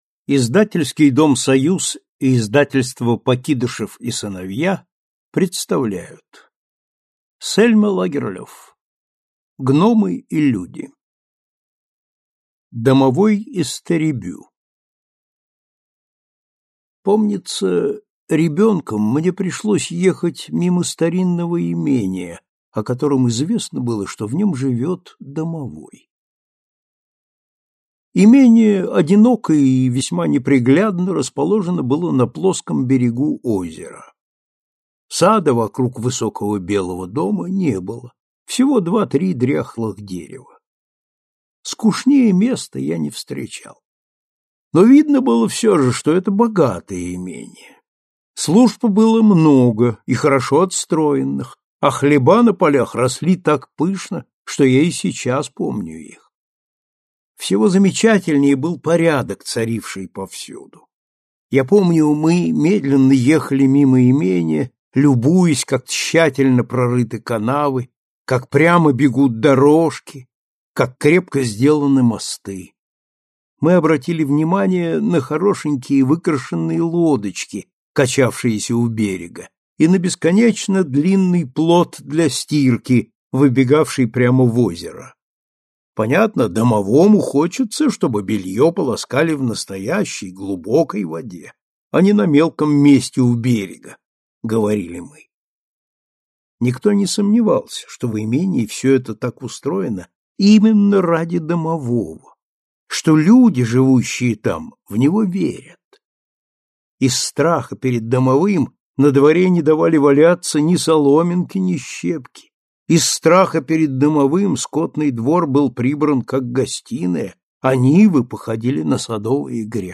Аудиокнига Домовой из Тэребю | Библиотека аудиокниг